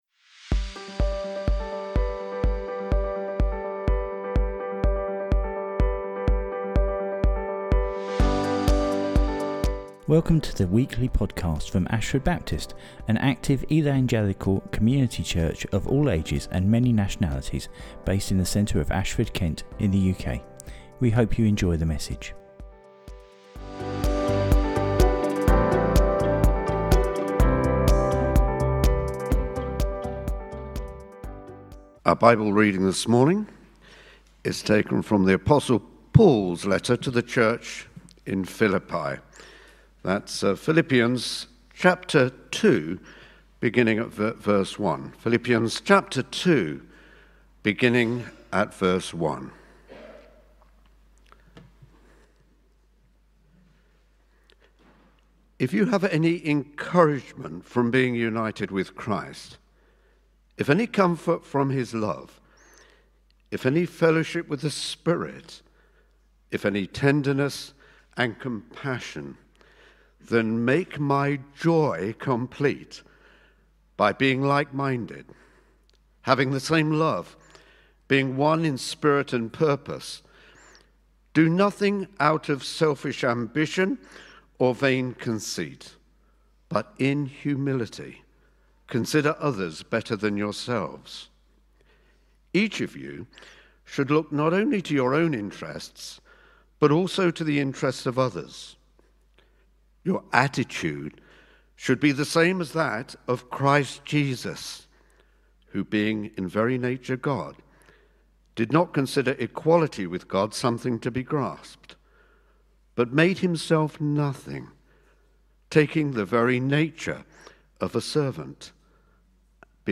The bible reading is from Philippians 2.